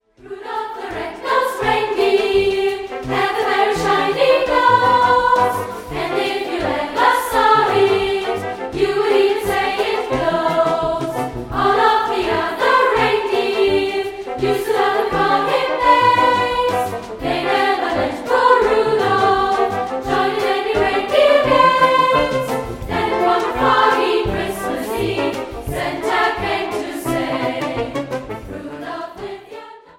Klavier
einfühlsame Klavierbegleitung